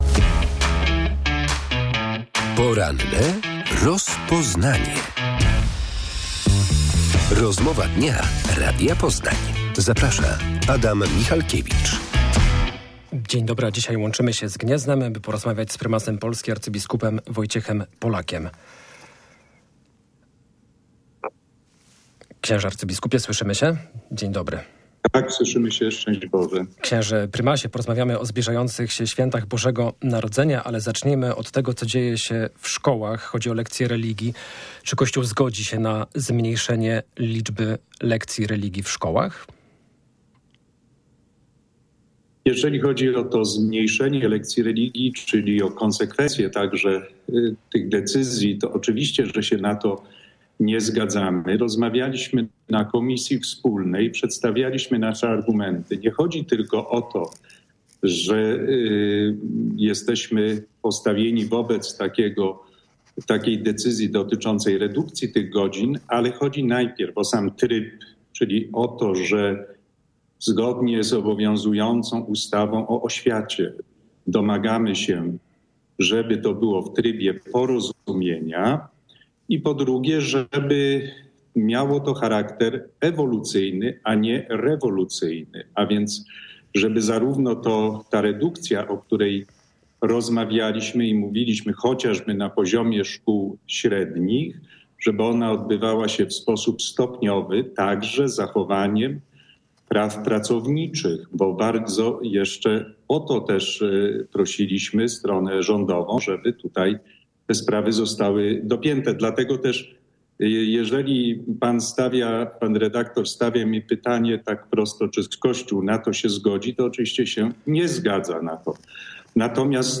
Co z lekcjami religii i przygotowaniach do Świąt Bożego Narodzenia? Gościem jest prymas Polski arcybiskup Wojciech Polak.